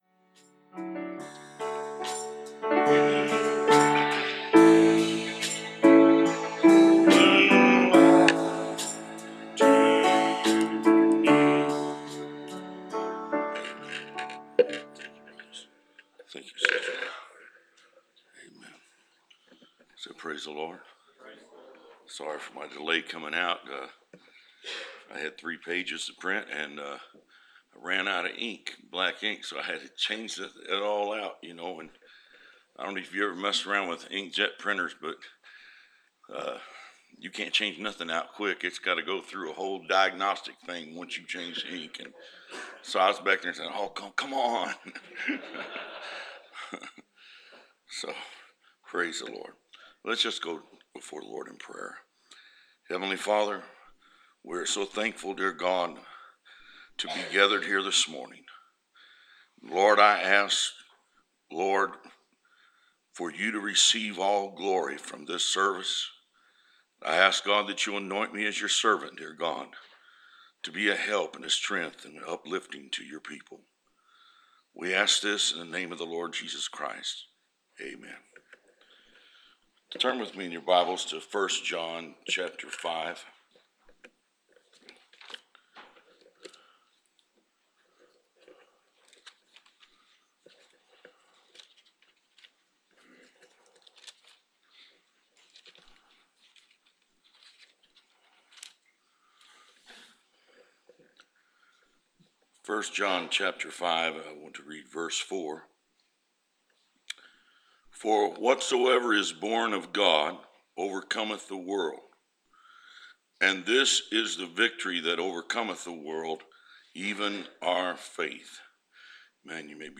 Preached August 30, 2015